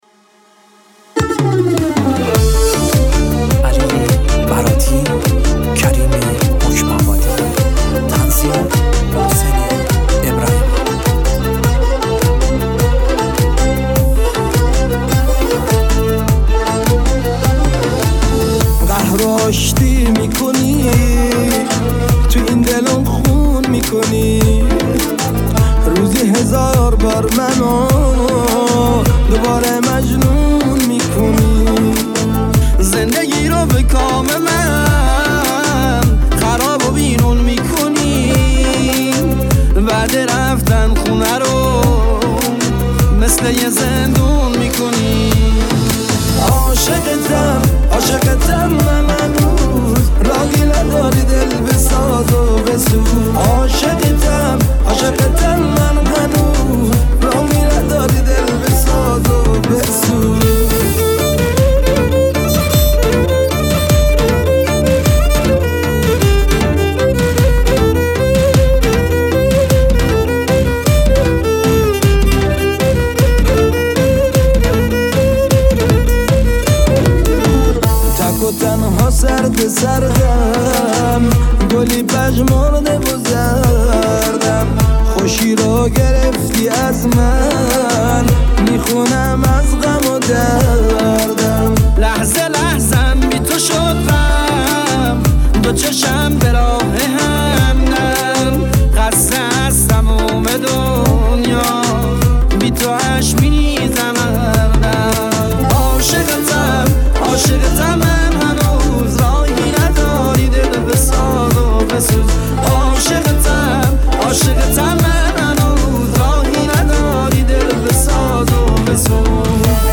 یکی از خواننده های پرشور و پرانرژی کرمانجی